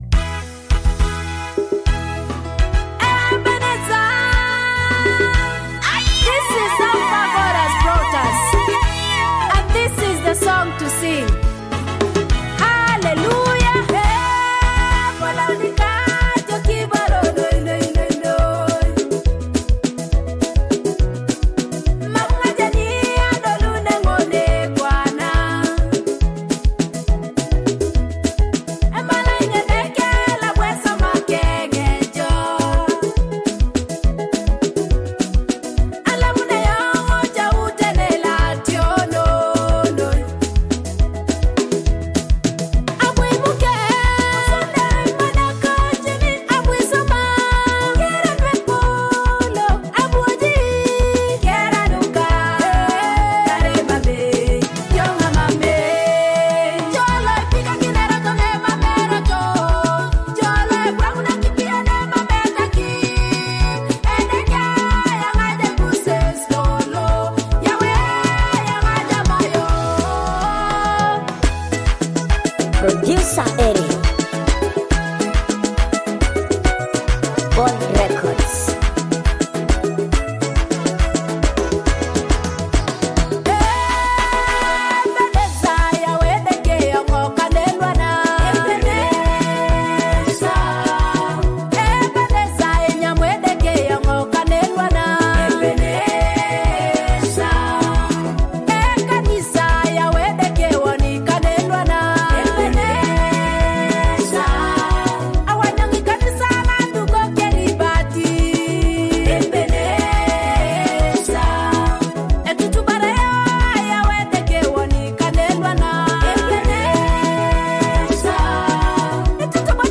heartfelt Ugandan gospel song